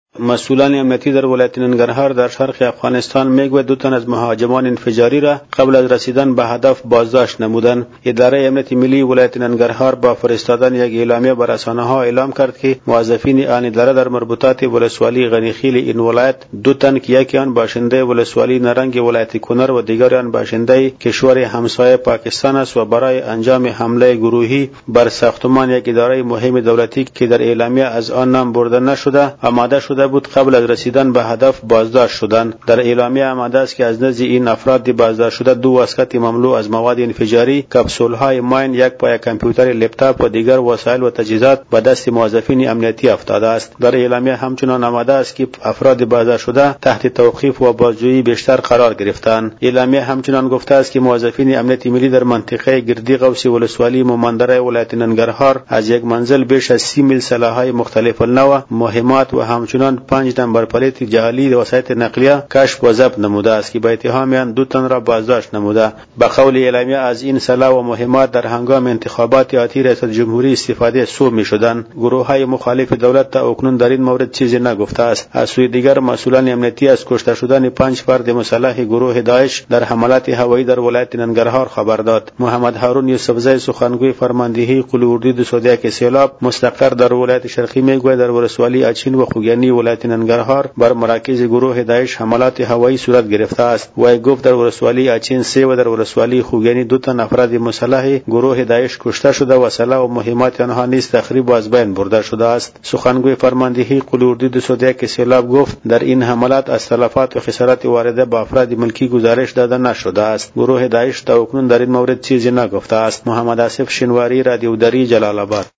جزئیات بیشتر در گزارش خبرنگار رادیو دری: